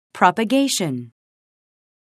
propagation 미 [prpəgéiʃən]